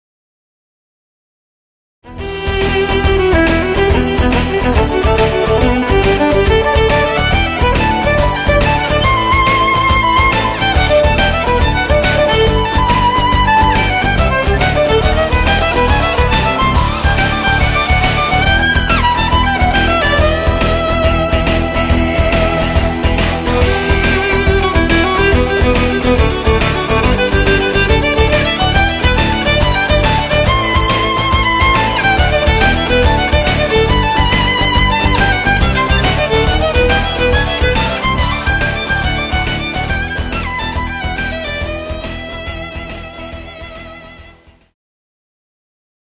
Electric String Trio
three electric violins